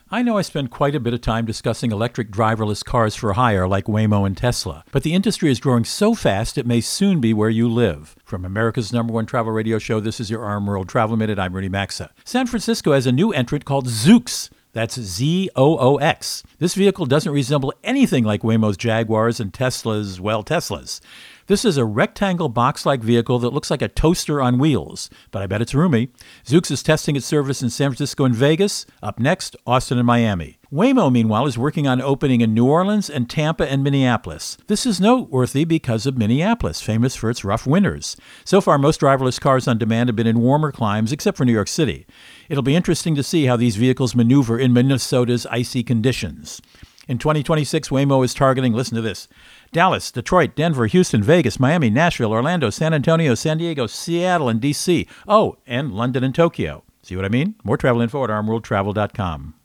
Co-Host Rudy Maxa | Driverless Cars Growth is Exploding